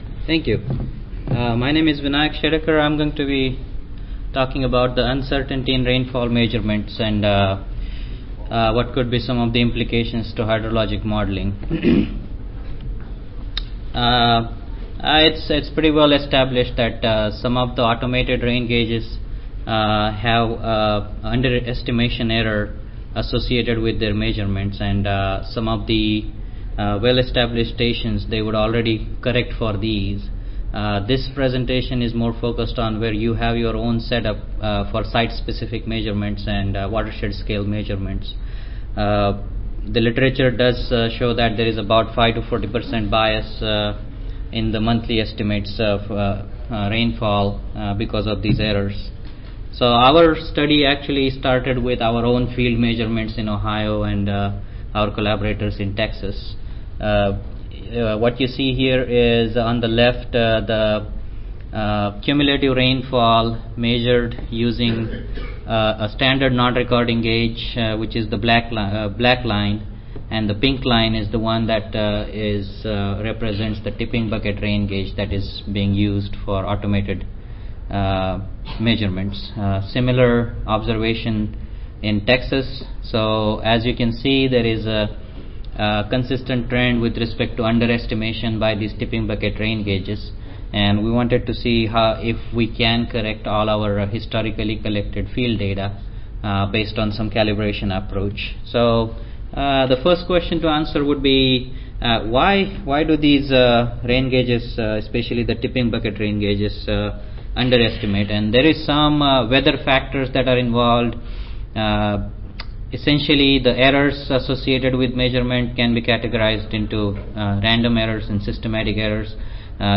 See more from this Division: ASA Section: Climatology and Modeling See more from this Session: Climatology and Modeling General Oral I
Recorded Presentation